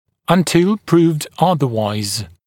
[ʌn’tɪl pruːvd ‘ʌðəwaɪz][ан’тил пру:вд ‘азэуайз]пока не будет доказано обратное